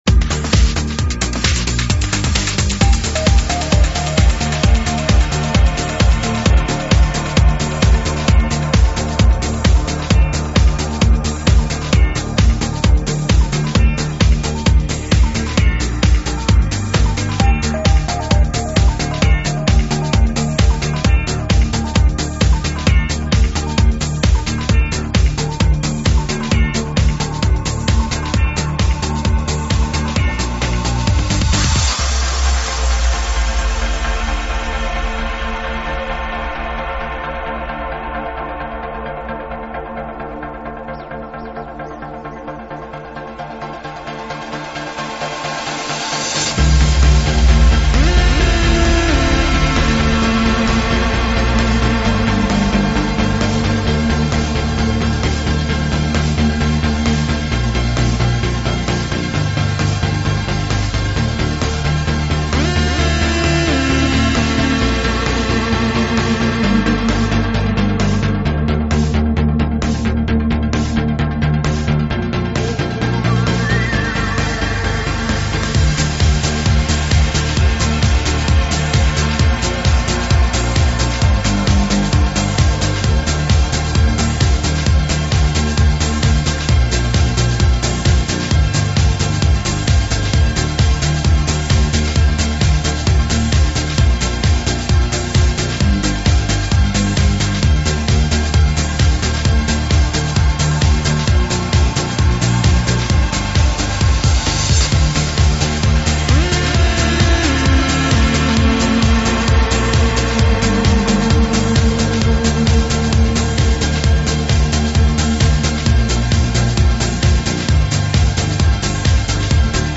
транс